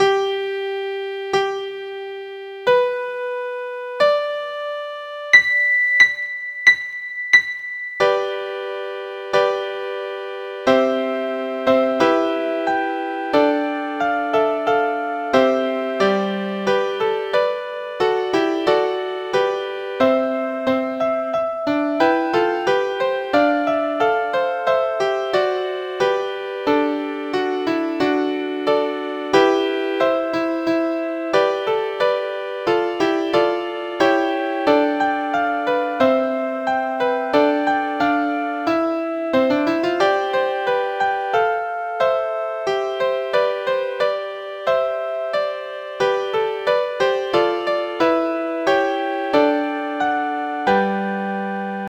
Audition Cut Audio Files
Mid-State Women (Palestrina)